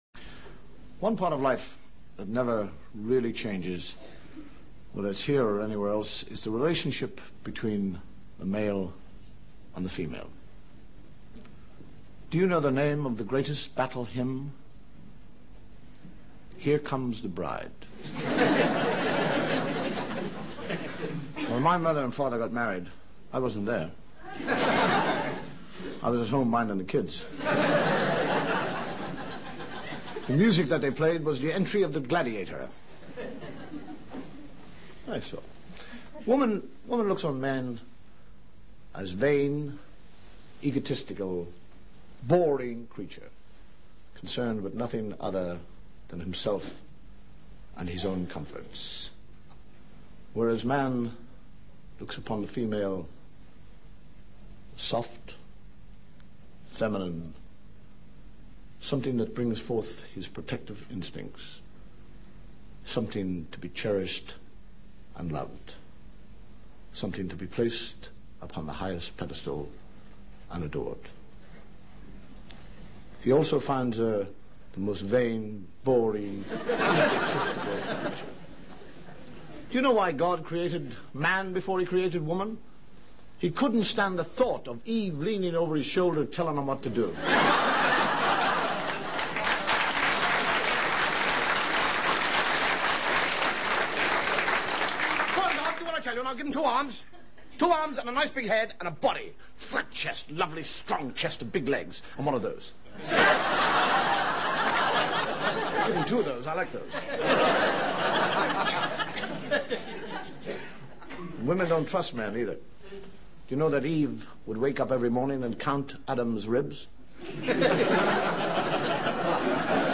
Comedy - Dave Allen